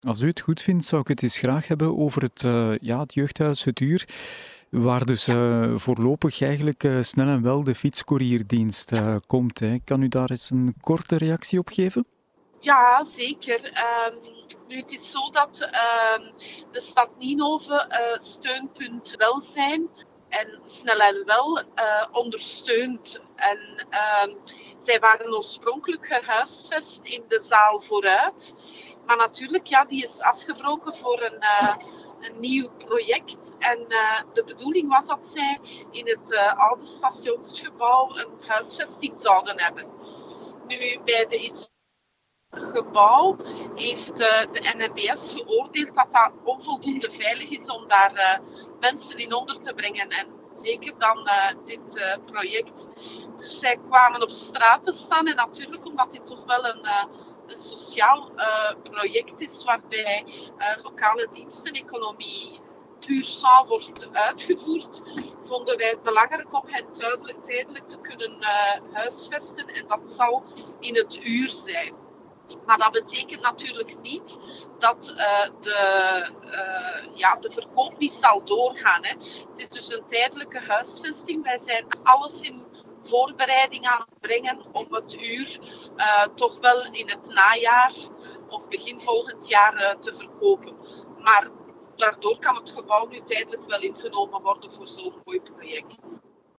Beluister hieronder het interview met burgemeester Tania De Jonge: